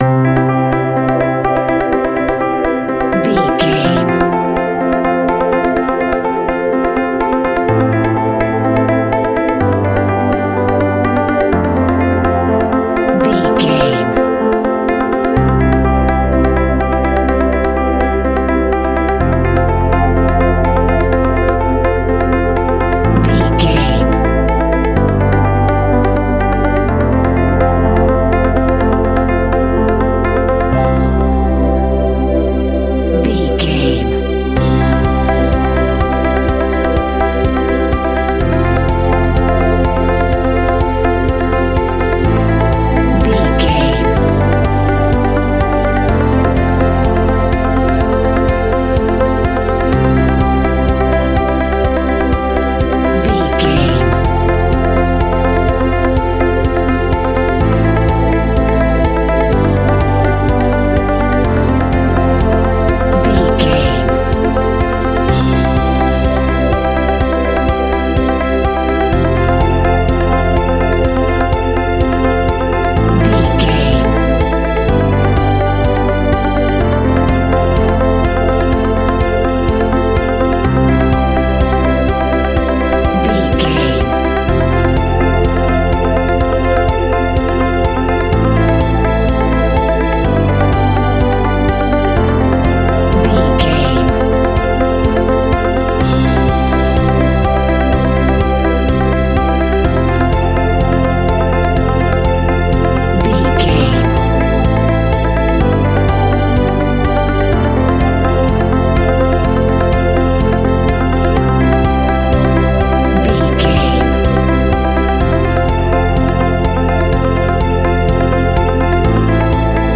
Uplifting
Ionian/Major
energetic
cheesy
instrumentals
indie pop rock music
guitars
bass
drums
piano
organ